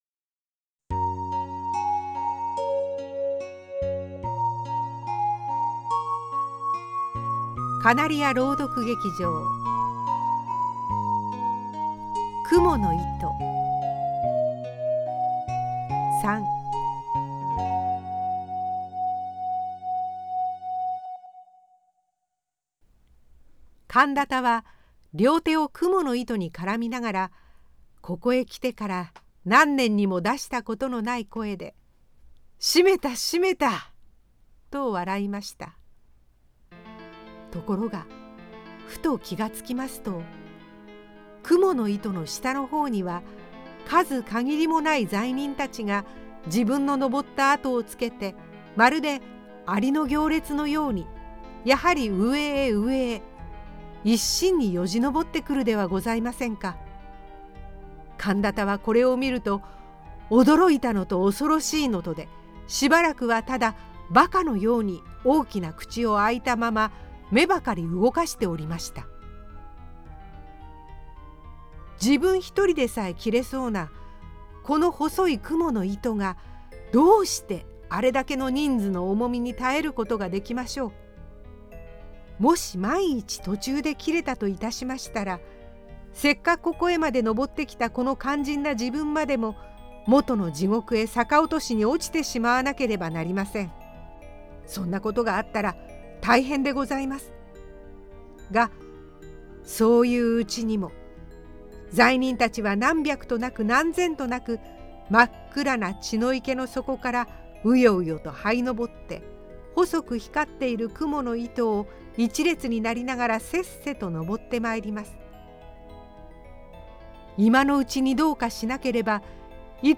お待たせしました、芥川龍之介「蜘蛛の糸」その３をお届けします。物語のクライマックスとも言える小説第二章の後半部分と再び極楽の様子が描かれた第三章を、その３としてまとめています。